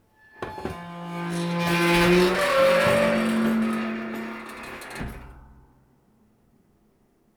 metal_creak6.wav